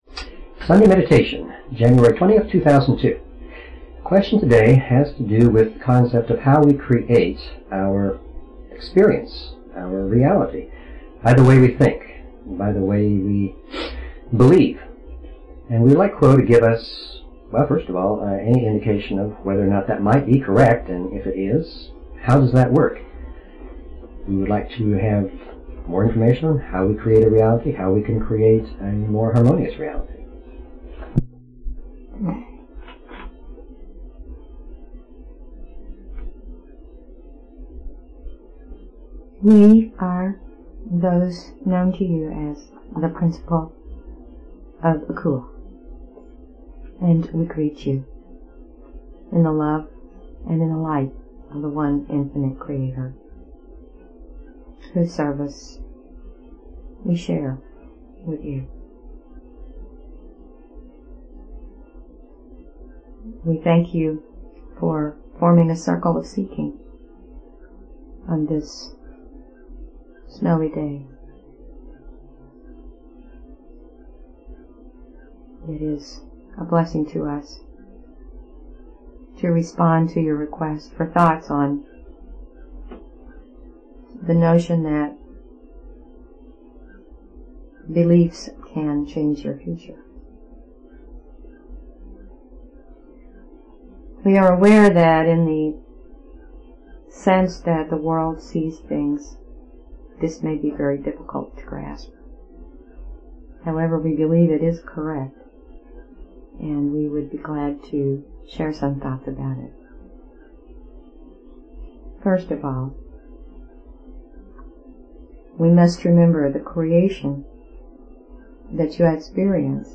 Talk Show Episode, Audio Podcast, LLResearch_Quo_Communications and Courtesy of BBS Radio on , show guests , about , categorized as